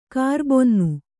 ♪ kārbonnu